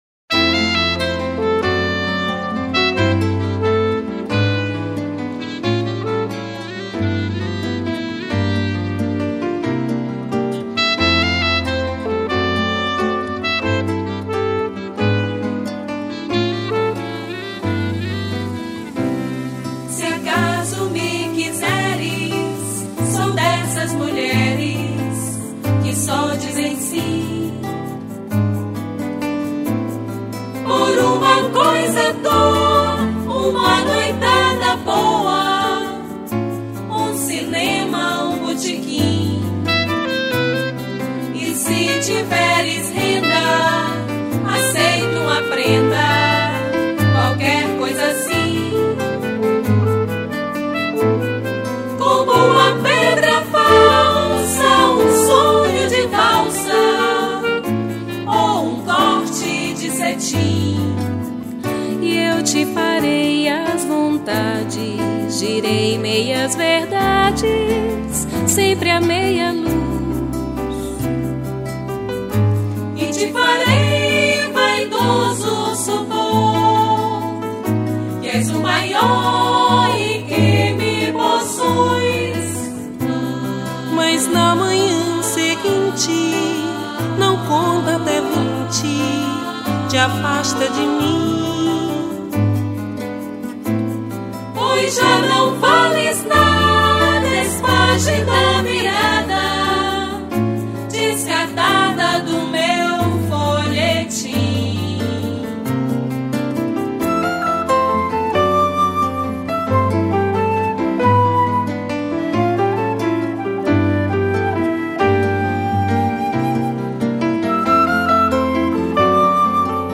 195   03:08:00   Faixa:     Mpb